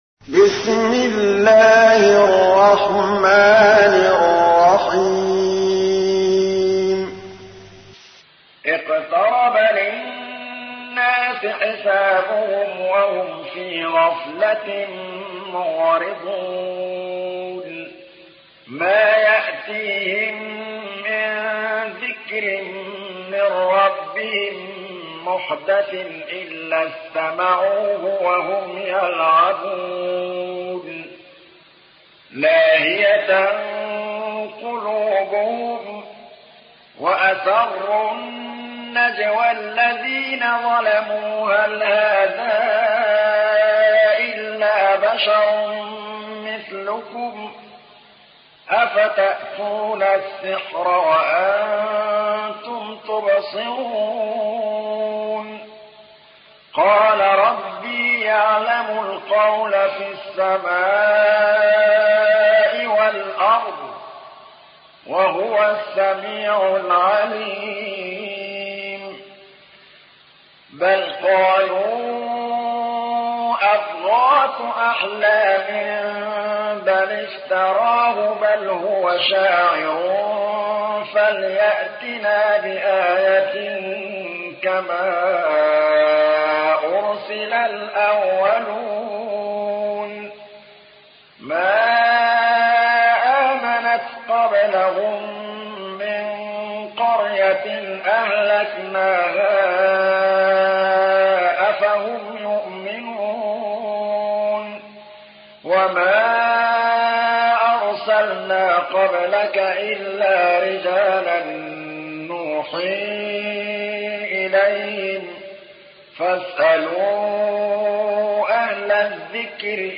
تحميل : 21. سورة الأنبياء / القارئ محمود الطبلاوي / القرآن الكريم / موقع يا حسين